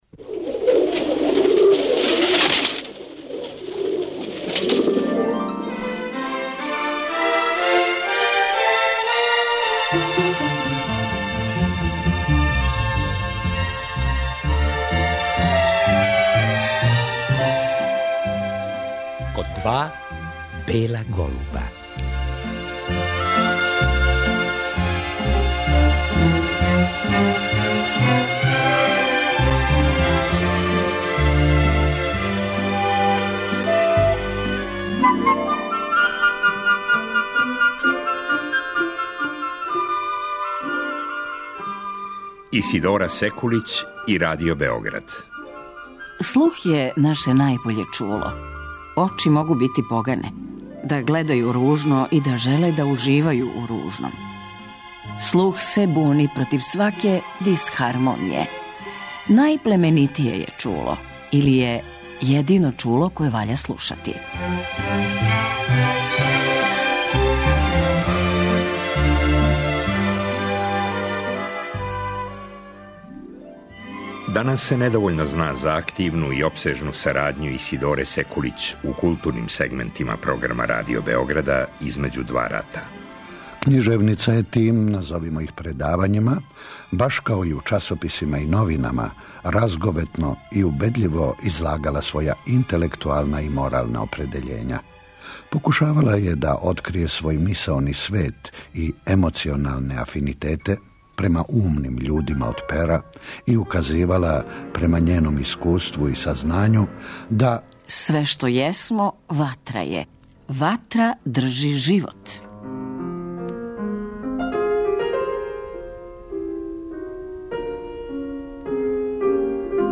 У Архиву Радио Београда чува се последњи снимак Исидоре Секулић „Служба“.
Прочитала га је на Радио Београду, пред смрт, 1958. године.
Поводом годишњице рођења књижевнице, 16. фебруар 1877. године, пренећемо три предавања Исидоре Секулић изречена на таласима Радио Београда.